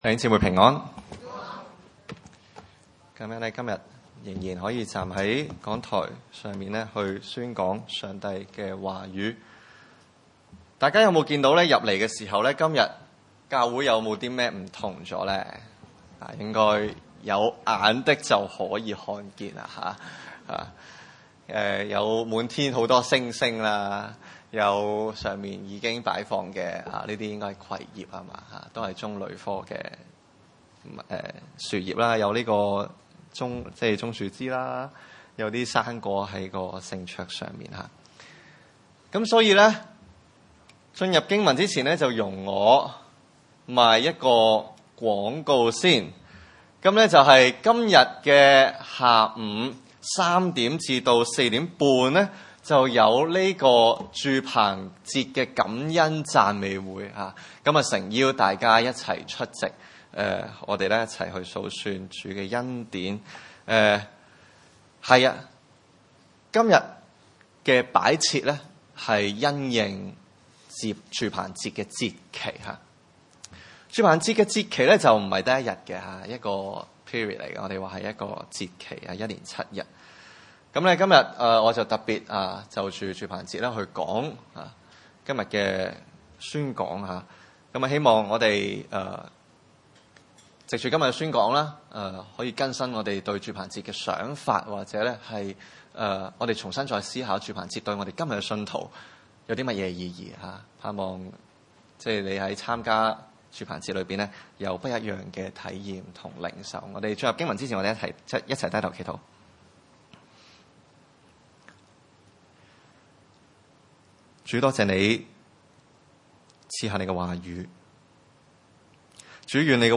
加拉太書 五：16-26 崇拜類別: 主日午堂崇拜 16 我 說 ， 你 們 當 順 著 聖 靈 而 行 ， 就 不 放 縱 肉 體 的 情 慾 了 。